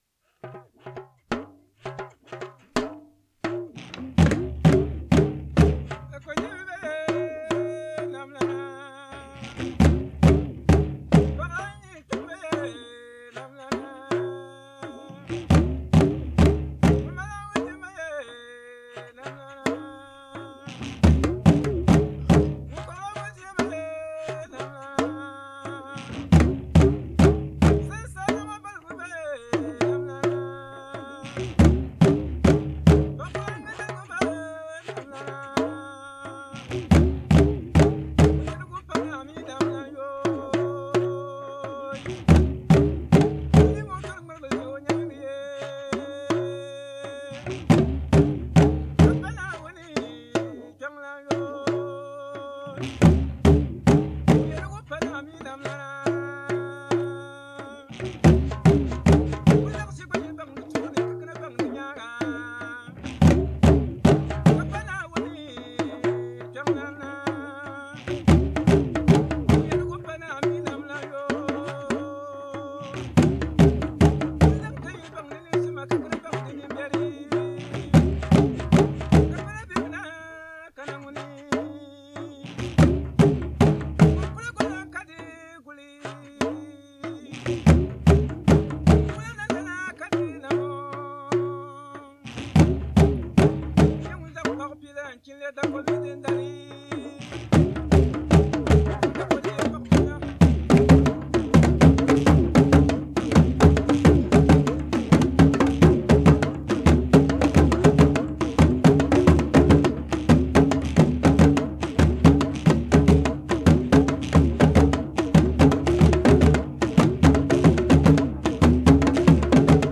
When drummers pick up their drums for any important event, the first things they beat are a number of proverbs praising God.
The phrases are responded to with the refrain, “Truly, it is the Chief of chiefs”: